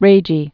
(rājē)